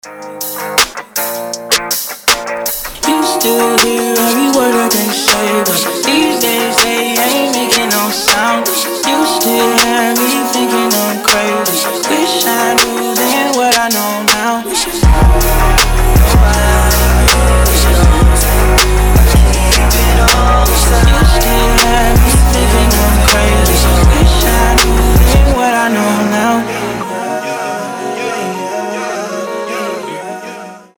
• Качество: 320, Stereo
Хип-хоп
электрогитара
RnB
Rap
приятный мужской голос